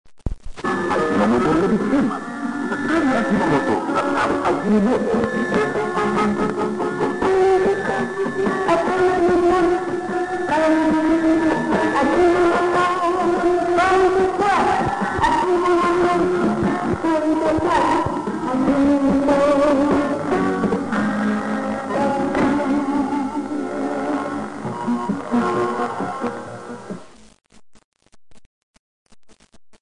RRI-Manado-AjinomotoCMMp.mp3